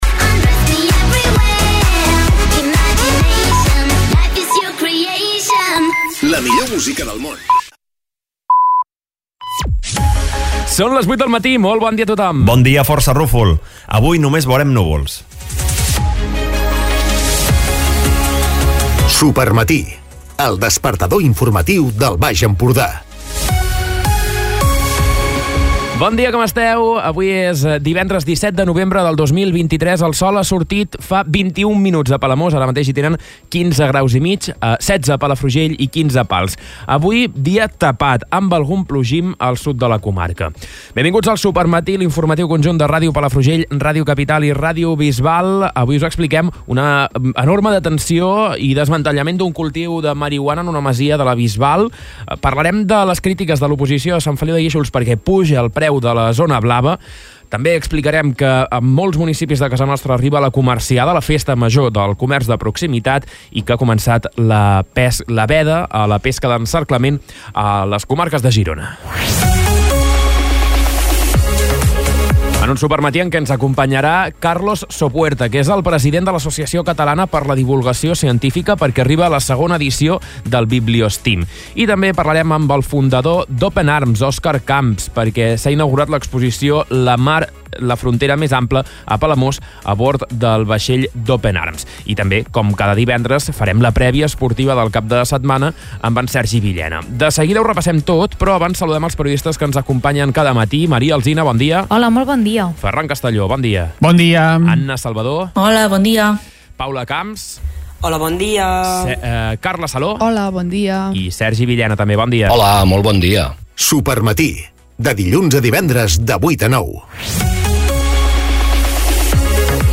Escolta l'informatiu d'aquest divendres